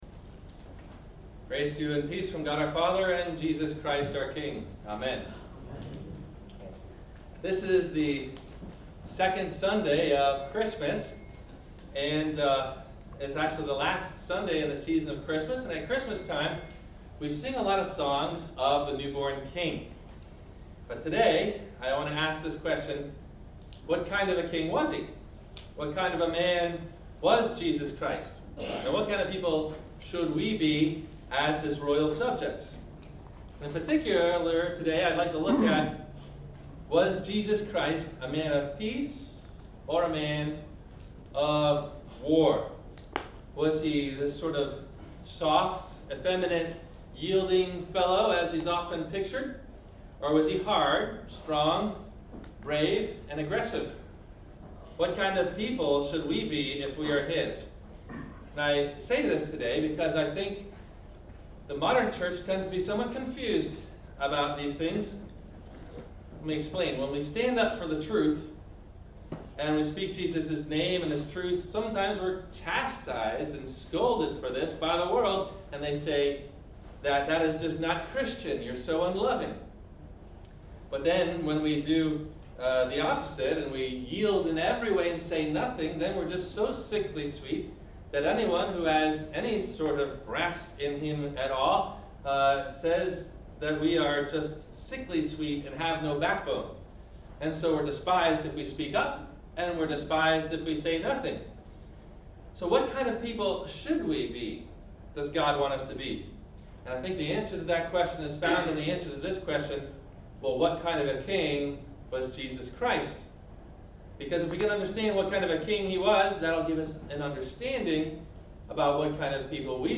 What Kind of King was Jesus ? – Sermon – January 05 2014